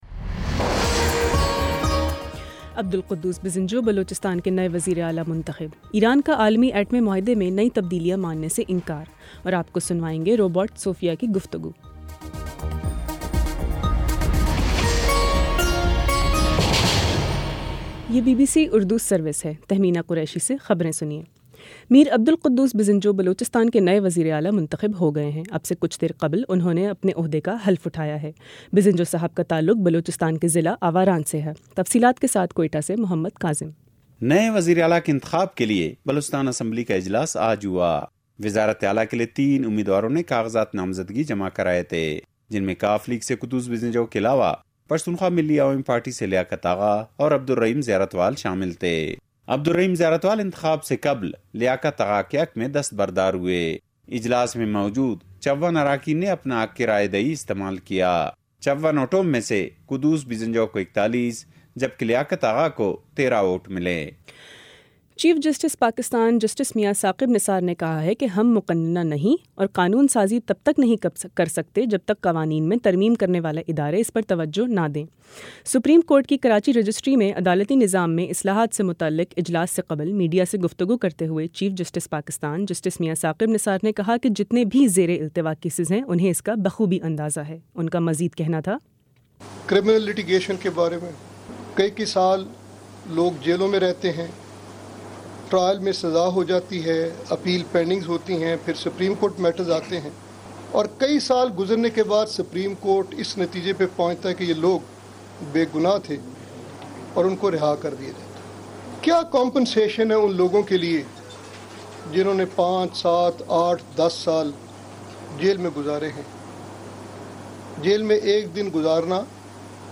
جنوری 13 : شام چھ بجے کا نیوز بُلیٹن